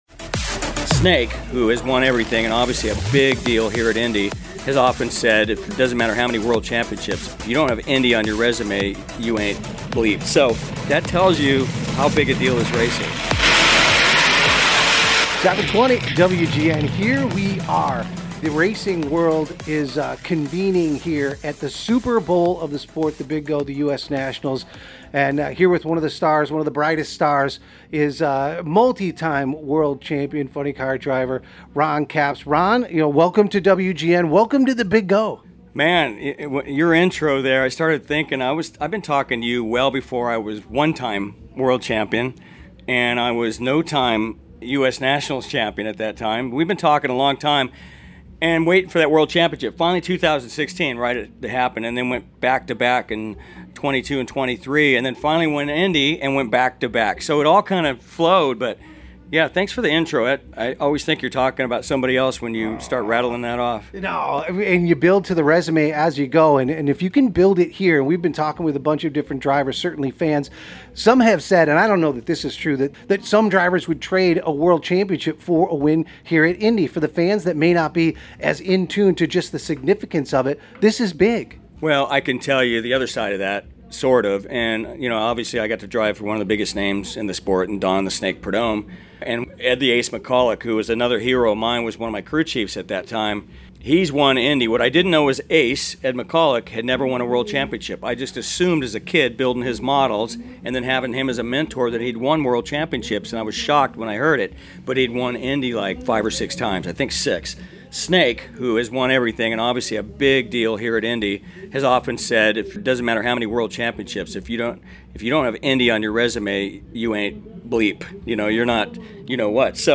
live from the U.S. Nationals. Hear as Ron shares the amazing history of “The BIG Go” and how fans and legends revere this race weekend as the standard for excellence in the sport. Listen as Ron shares memories of recent Indy successes and how all-time greats like Don “The Snake” Prudhomme stay connected to Ron and racing during U.S. Nationals.